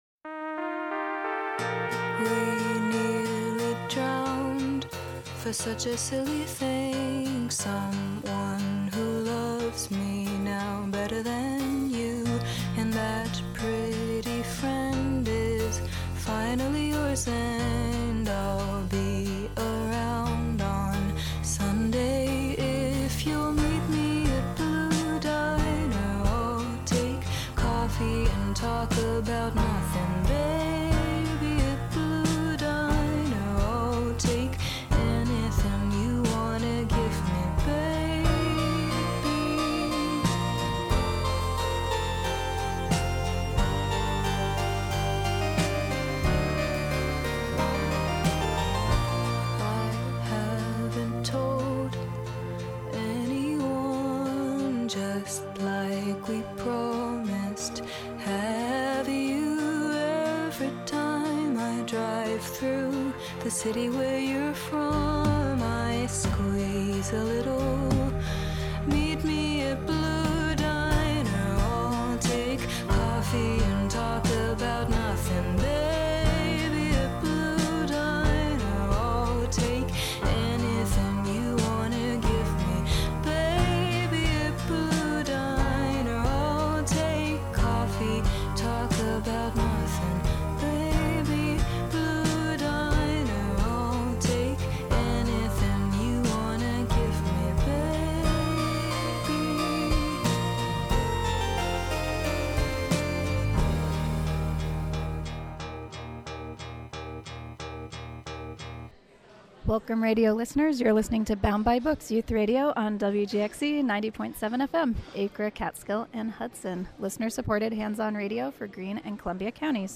Youth Radio